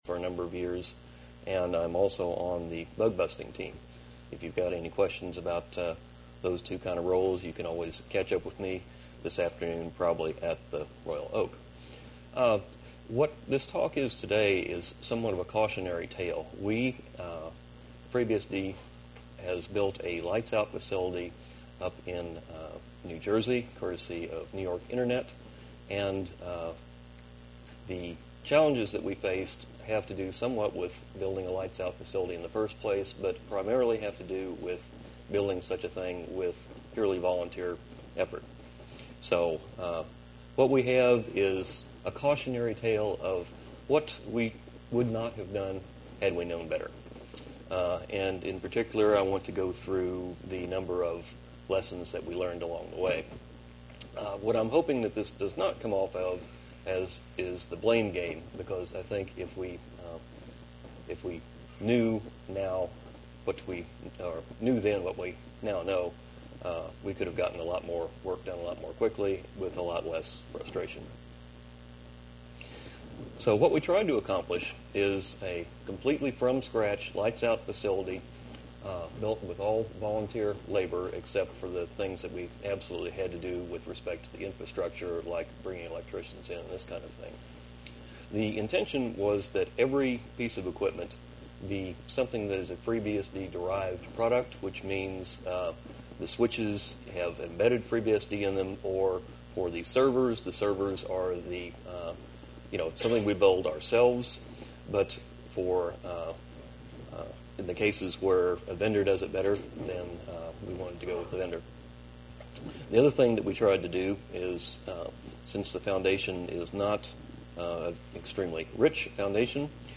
Event type Lecture